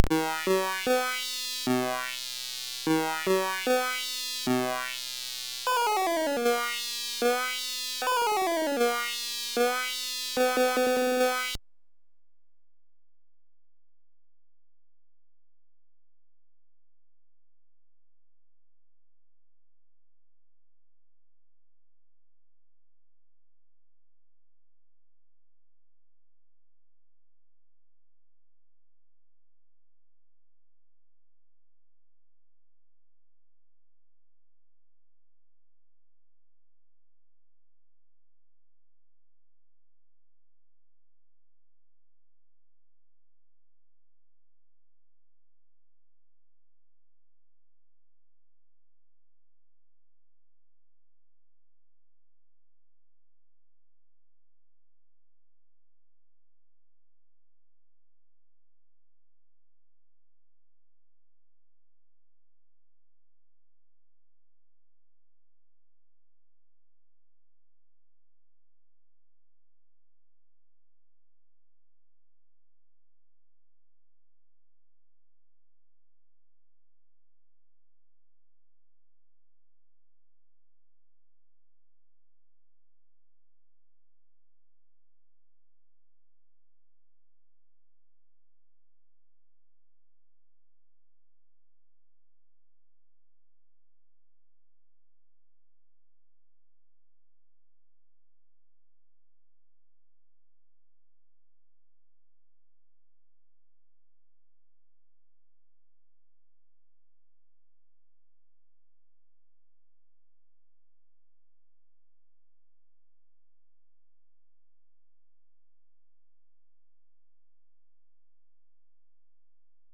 Gazette: SID64 / SID64.D64 / sitar.mus ( .mp3 ) < prev next > Commodore SID Music File | 2022-09-20 | 163b | 1 channel | 44,100 sample rate | 3 minutes